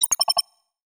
Holographic UI Sounds 23.wav